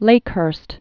(lākhürst)